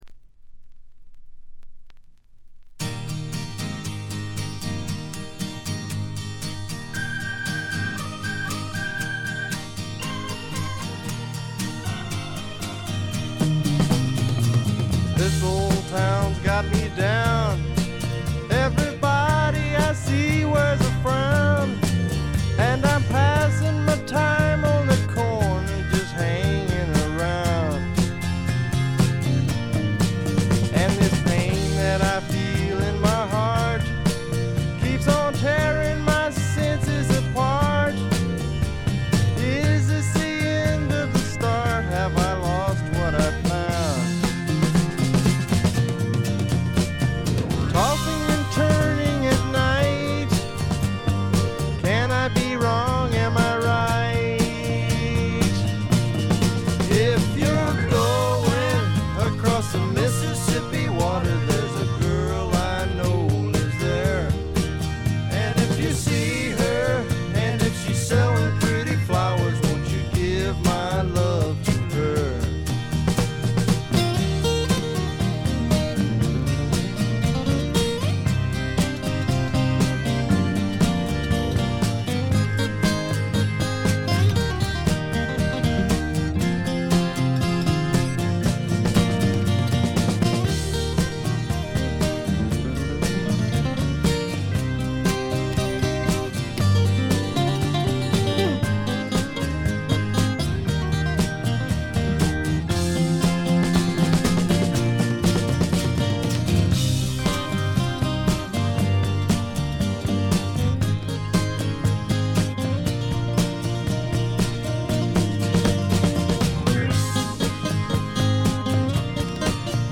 部分試聴ですがごくわずかなノイズ感のみ。
質感は哀愁のブリティッシュ・スワンプそのまんまであります。
試聴曲は現品からの取り込み音源です。